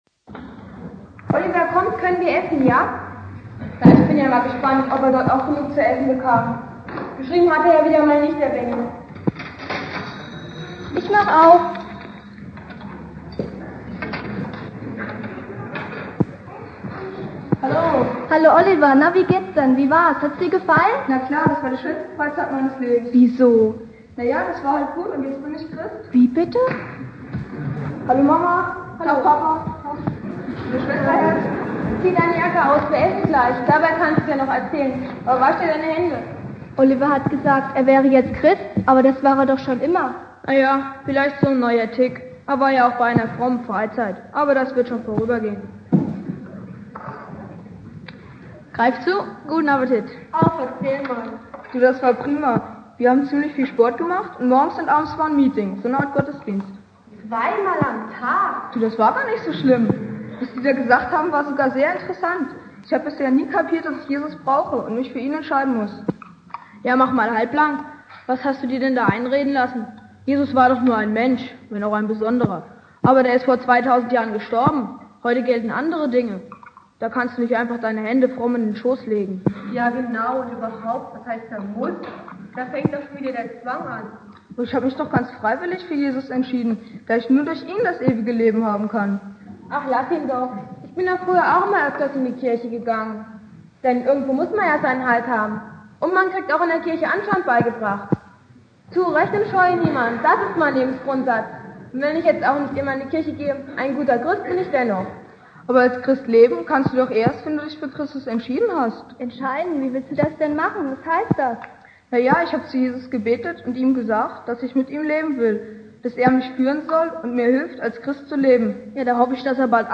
Anspiel und Predigt